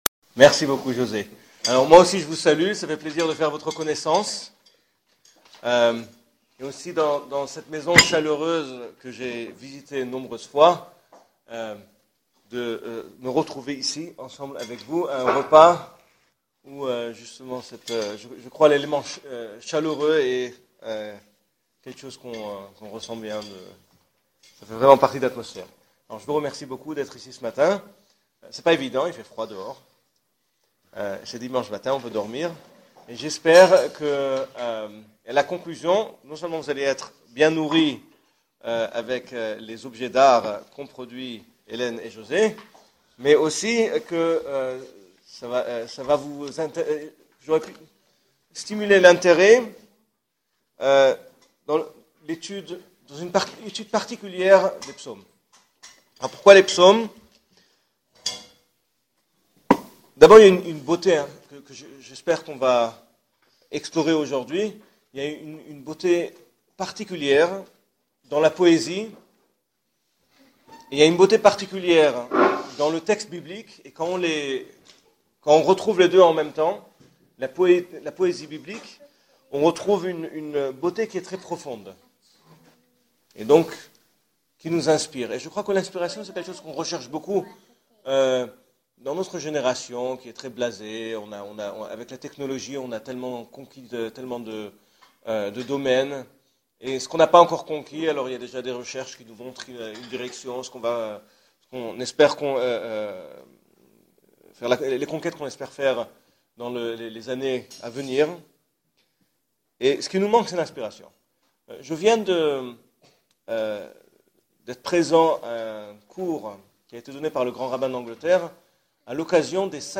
Le psaume 92 – cours multimédia en français
Découvrez l’étude des psaumes et comment interpréter les midrachim (l’éxégèse talmudique) à leurs propos, grace à cette conférence mp3.